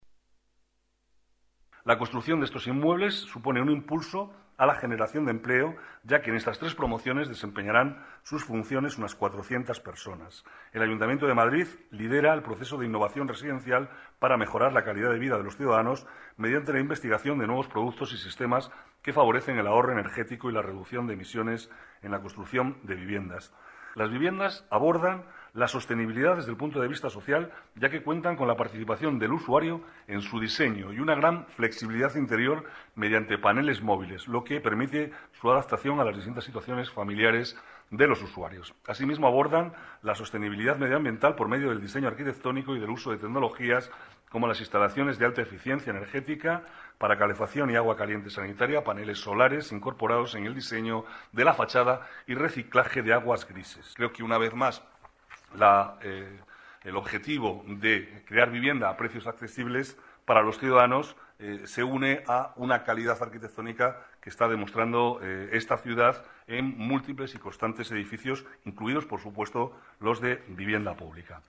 Nueva ventana:Declaraciones del vicealcalde, Manuel Cobo: nuevas promociones viviendas protegidas